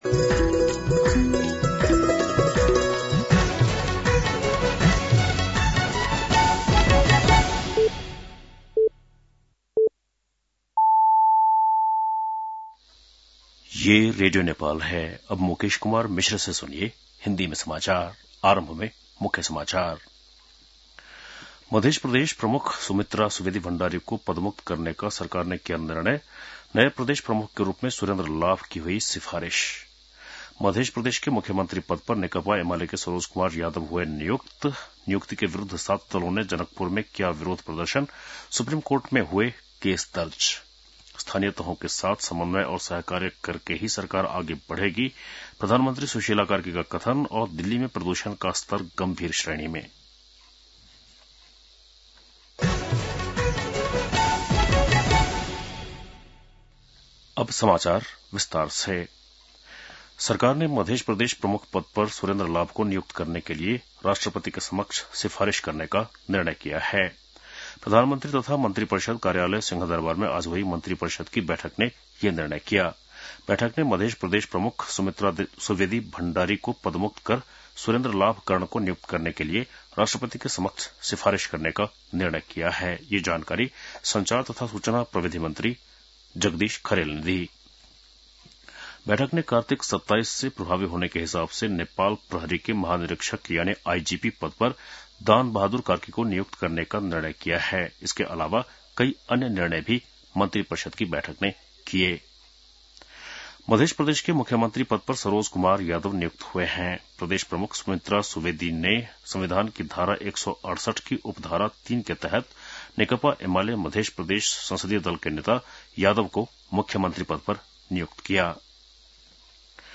बेलुकी १० बजेको हिन्दी समाचार : २४ कार्तिक , २०८२
10-PM-Hindi-NEWS-7-24.mp3